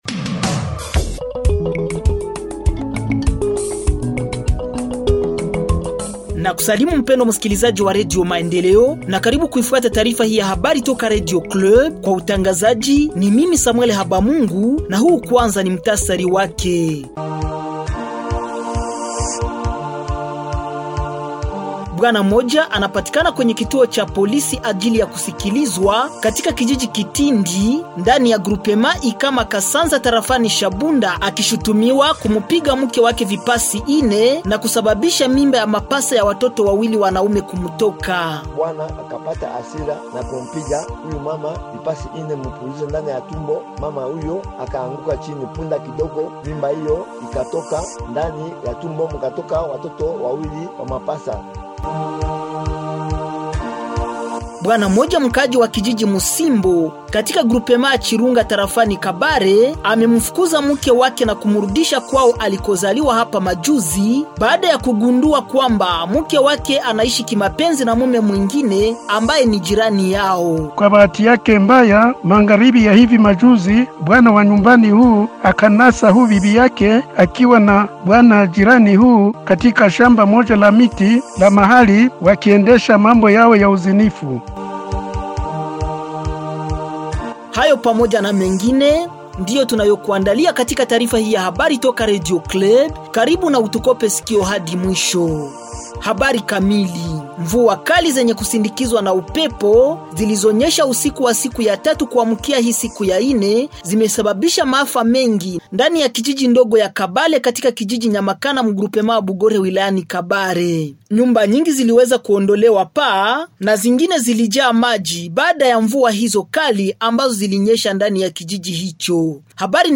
Journal de Radio-Club